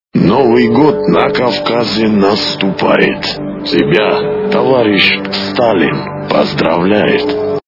При прослушивании Голос Сталина - Новый год на Кавказе качество понижено и присутствуют гудки.